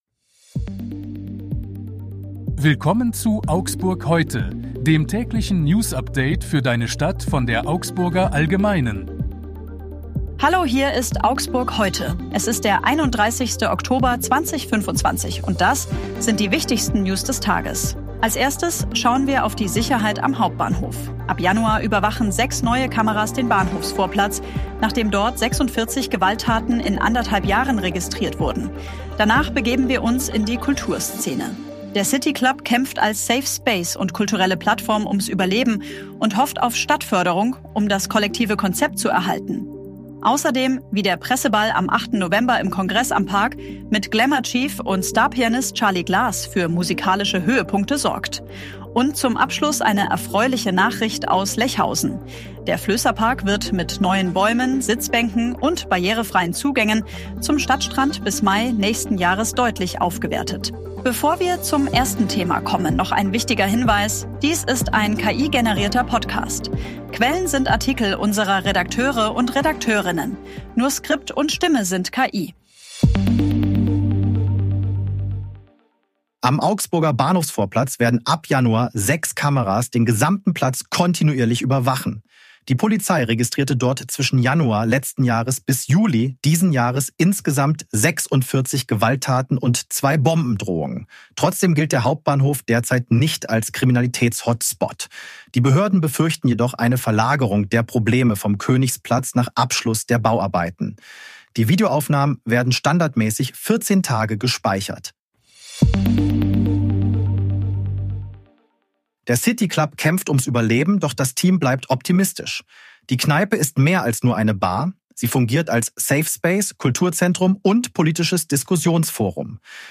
Hier ist wieder das tägliche Newsupdate für deine Stadt.
möchte Flößerpark aufhübschen Dies ist ein KI-generierter Podcast.
Skript und Stimme sind KI.